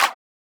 VEE Clap 017.wav